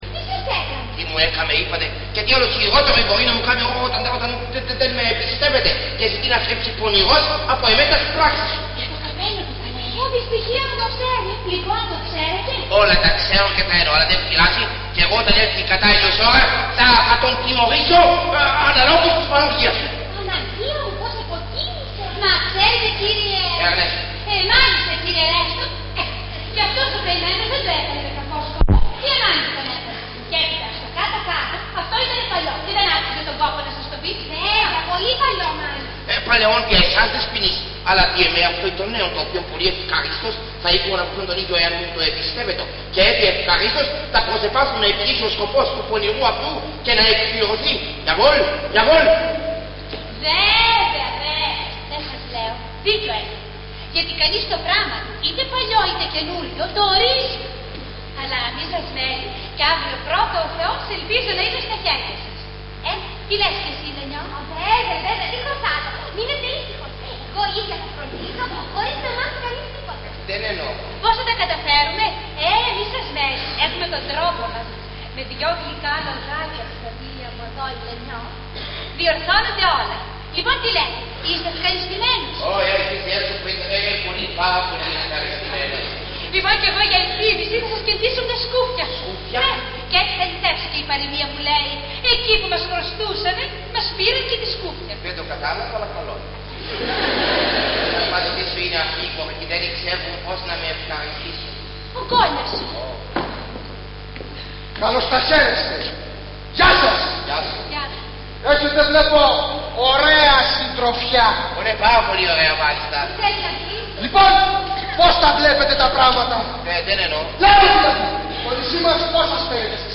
Ηχογράφηση Παράστασης